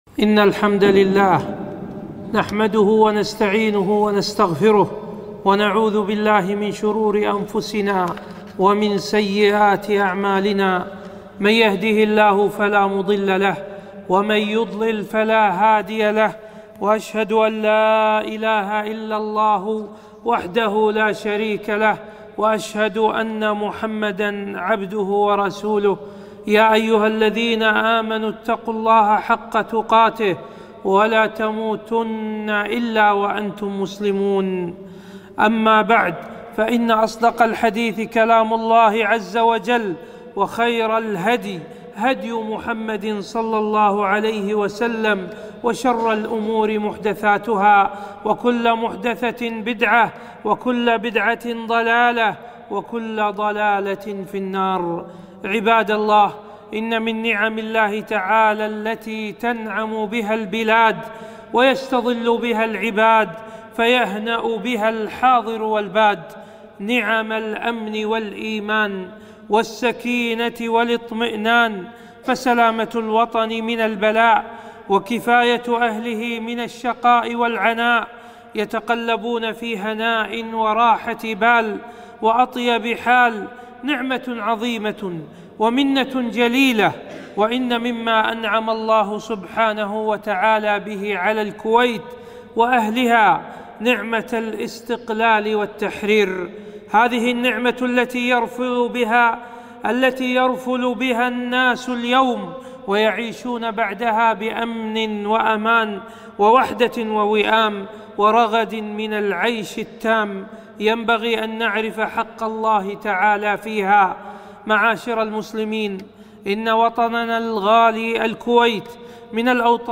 خطبة - نعمة التحرير